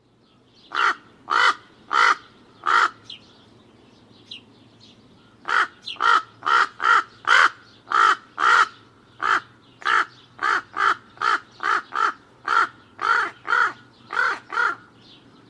kids-commonravenaudio.mp3